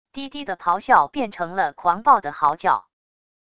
低低的咆哮变成了狂暴的嚎叫wav音频